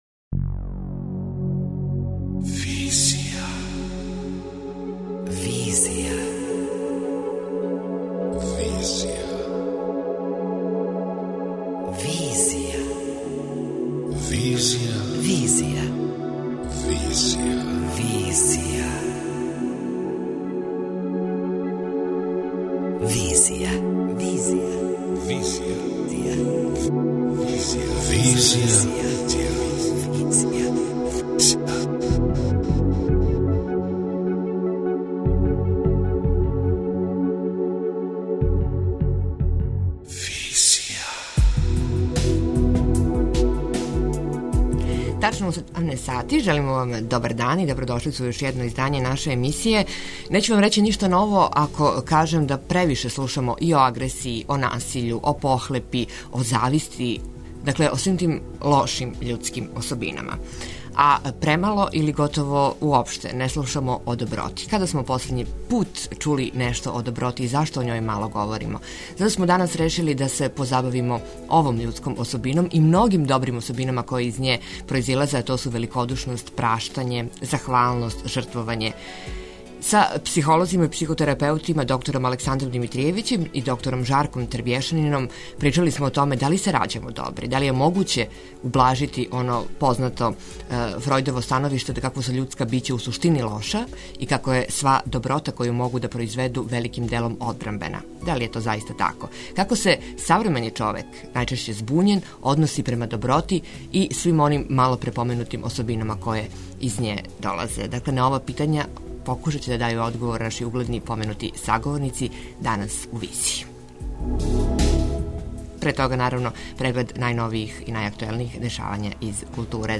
преузми : 28.05 MB Визија Autor: Београд 202 Социо-културолошки магазин, који прати савремене друштвене феномене.